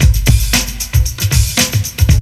ELECTRO 07-L.wav